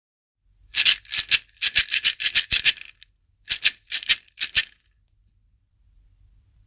バオバブの実シェイカー
まるまる一個バオバブの実を使ったユニークなシェイカーです。
バオバブを振ると、中の種がカラカラ、やさしい音のシェイカーです。
素材： バオバブの実